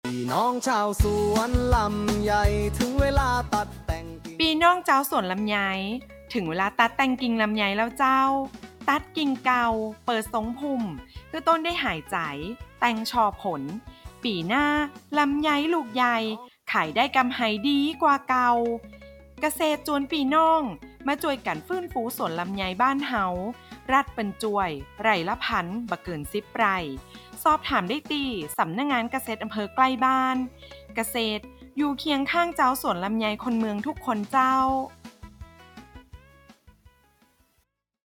SPOT RADIO